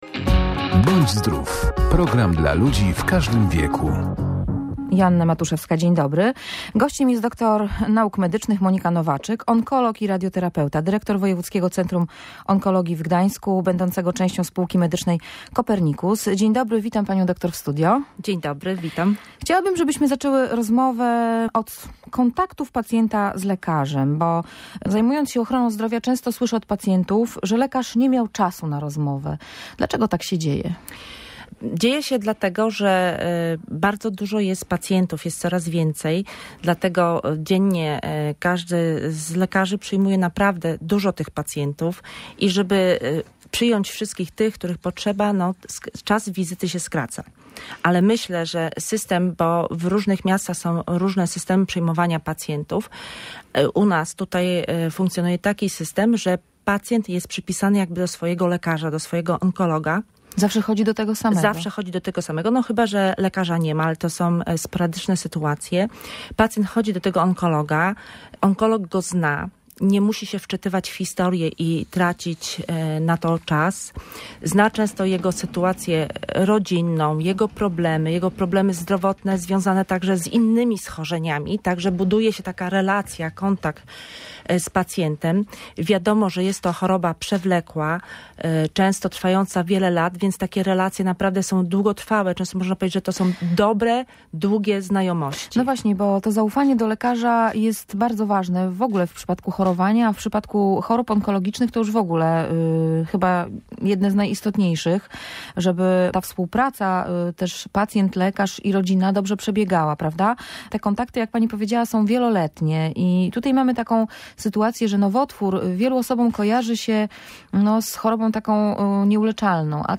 Odpowiadała też na pytania słuchaczy.